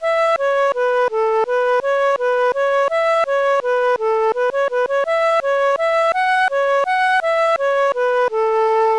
Instruments Flute